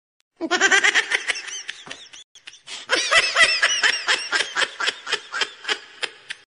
Meme Baby Laugh